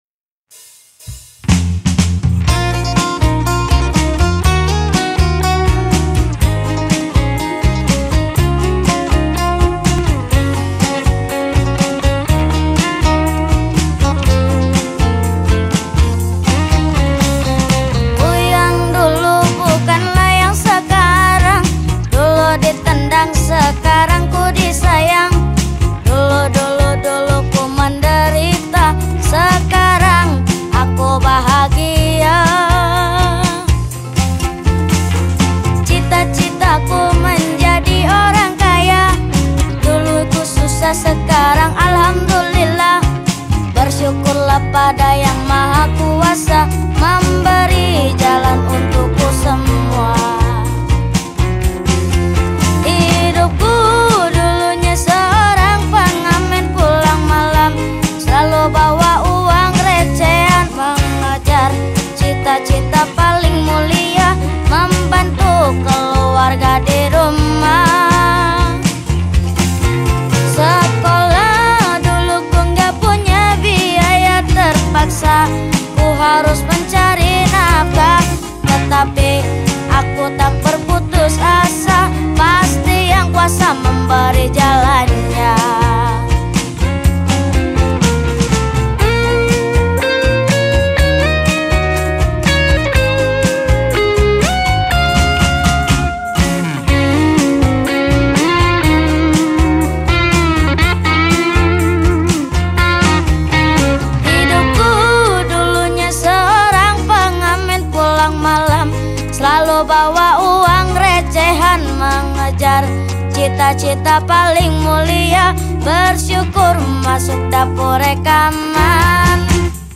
Indonesian Song